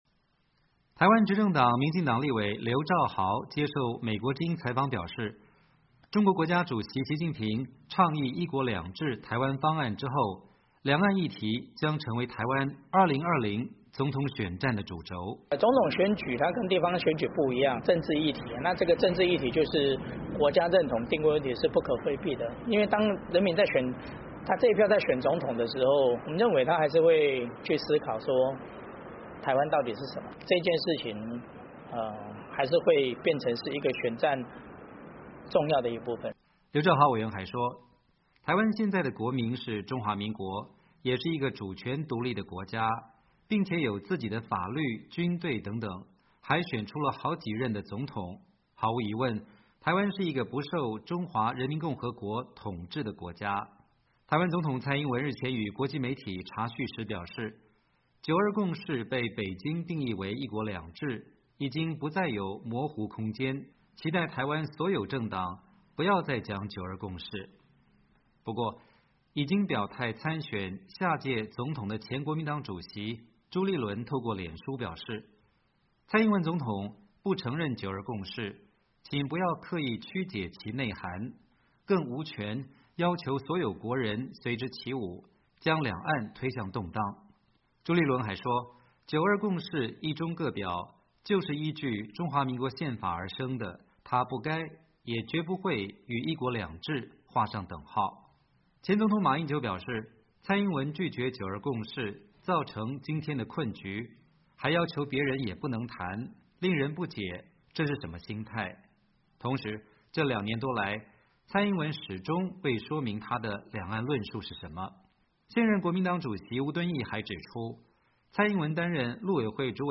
台湾执政党民进党立委刘櫂豪接受美国之音采访表示，中国国家主席习近平倡议一国两制台湾方案之后，两岸议题将成为台湾2020总统选战的主轴。